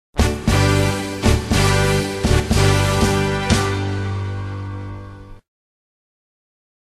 Tusch Download
karnevals-tusch_1.mp3